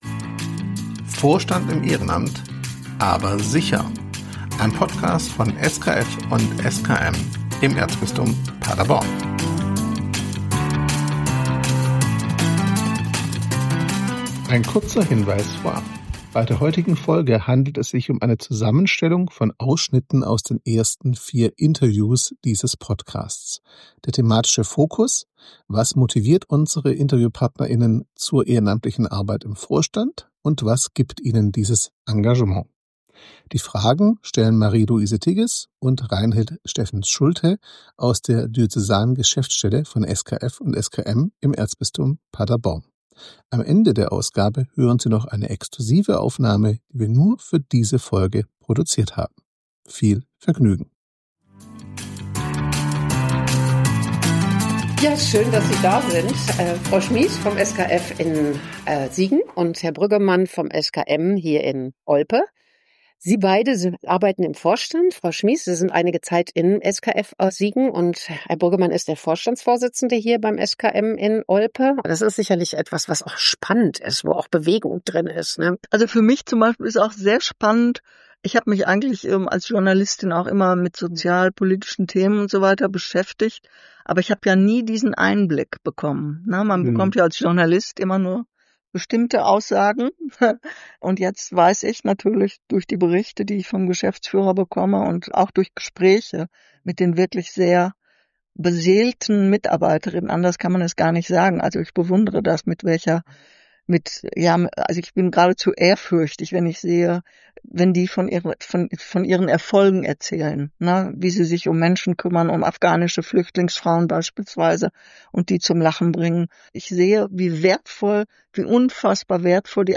Die Moderatorinnen
eine Zusammenstellung von Interviews mit engagierten Vorstandsmitgliedern